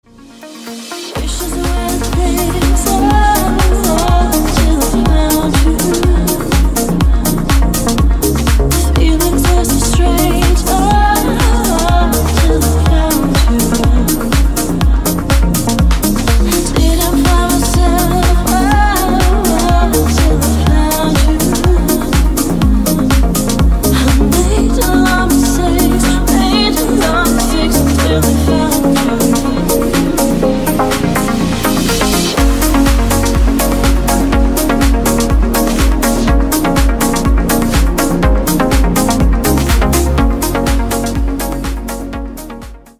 • Качество: 160, Stereo
deep house
Electronic
спокойные
чувственные
красивый женский голос